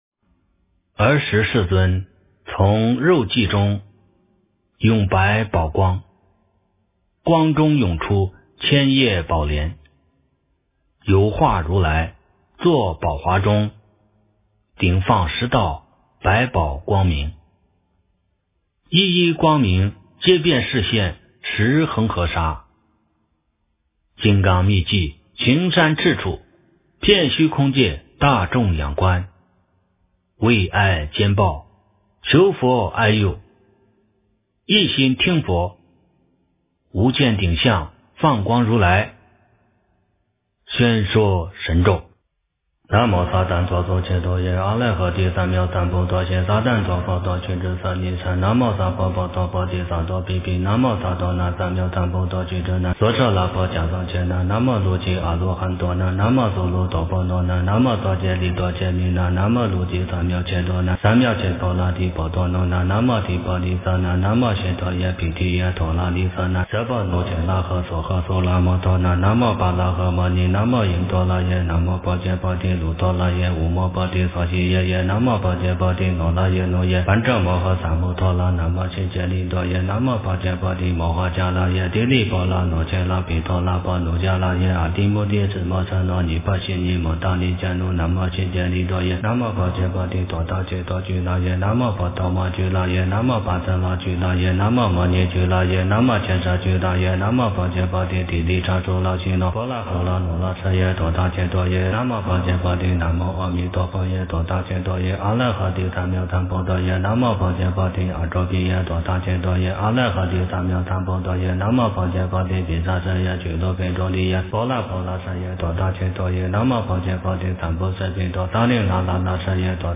诵经
佛音 诵经 佛教音乐 返回列表 上一篇： 《心经》 下一篇： 大般若波罗蜜多经第475卷 相关文章 长寿祈愿吉祥颂 回向念颂 长寿祈愿吉祥颂 回向念颂--风潮唱片...